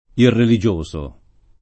irreligioso [ irreli J1S o ] agg.